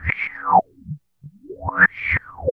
23 REVERSE-R.wav